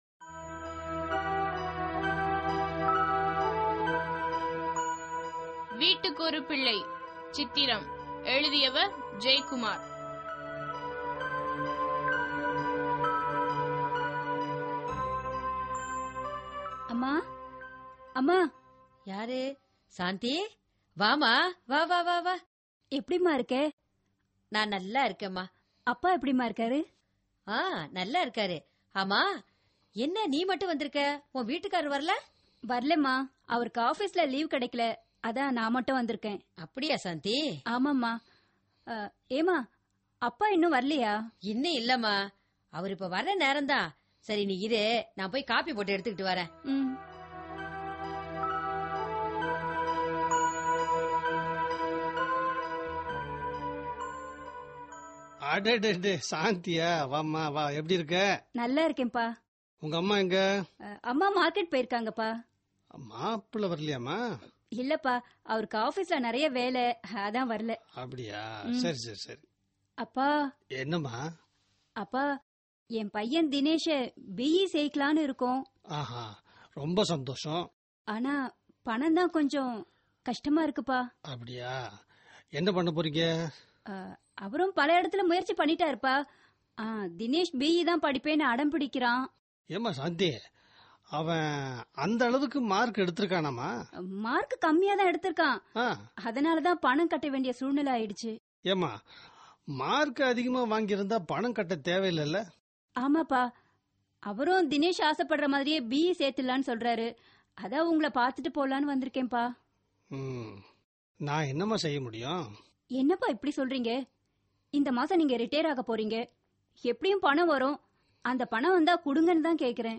Directory Listing of mp3files/Tamil/Dramas/Social Drama/ (Tamil Archive)